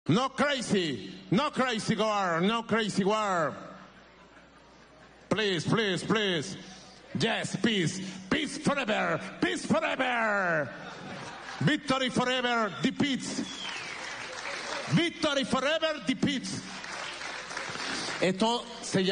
no crazy war please please please maduro Meme Sound Effect